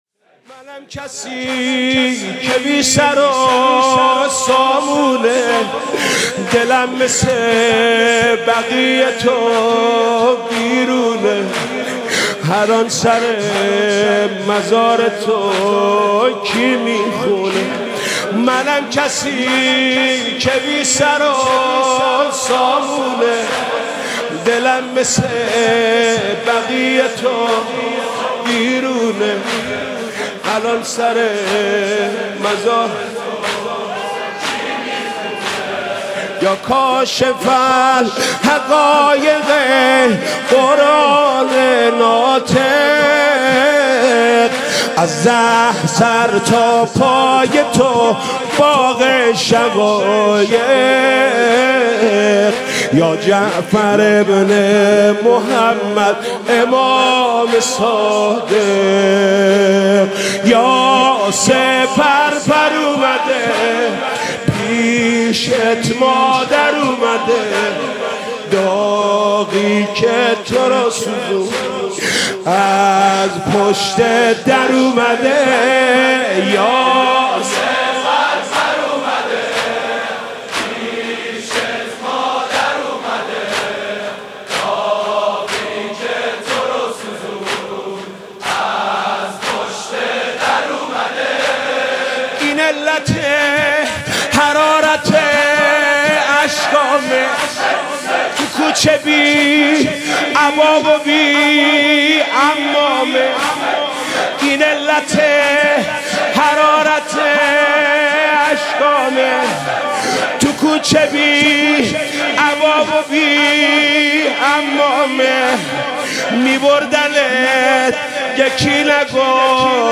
«شهادت امام صادق 1395» زمینه: منم کسی که بی سر و سامونه